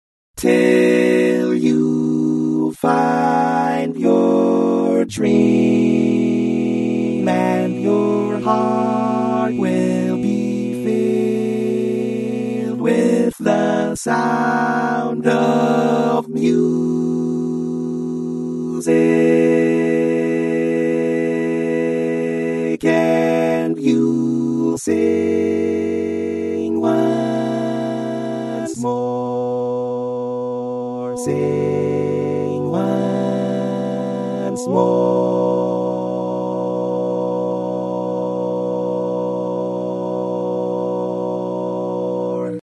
Key written in: B Major
Type: Barbershop